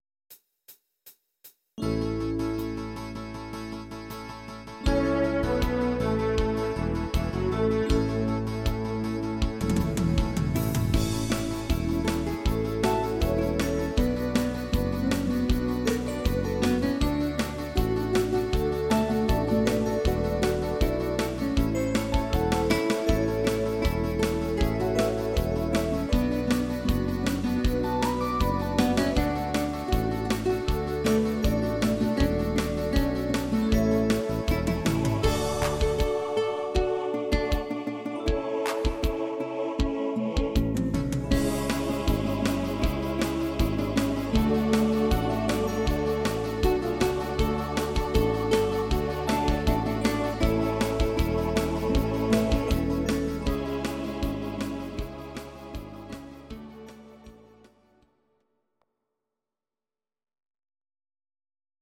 Audio Recordings based on Midi-files
Our Suggestions, Pop, German, Medleys